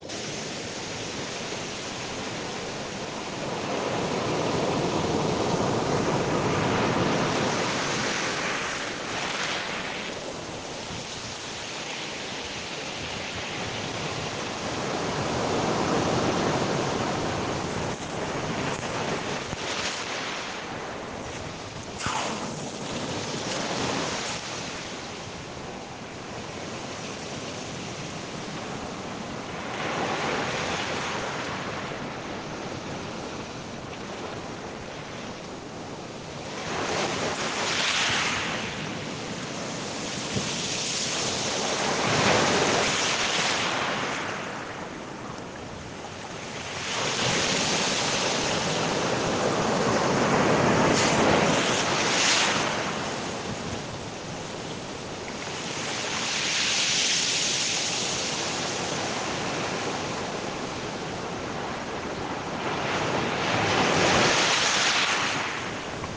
Playa Conchal GUANACASTE